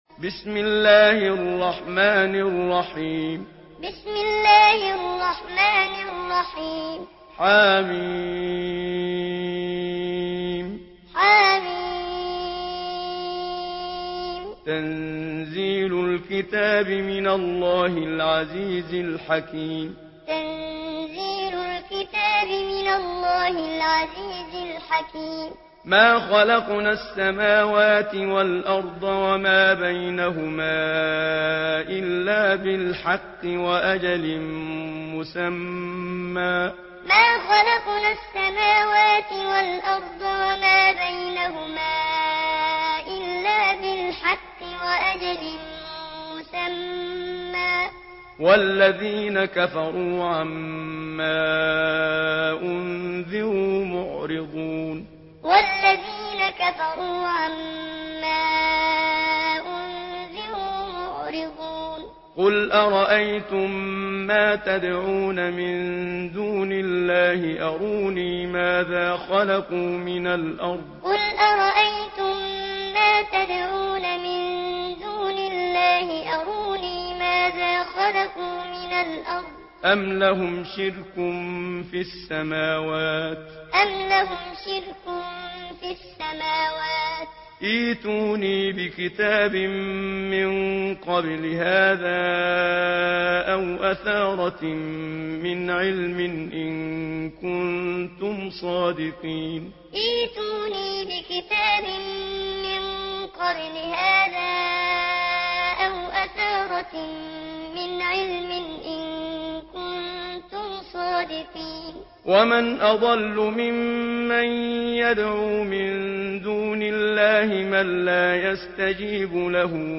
Surah আল-আহক্বাফ MP3 by Muhammad Siddiq Minshawi Muallim in Hafs An Asim narration.